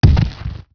boulder1.wav